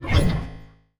Robotic Servo Notifcation 2.wav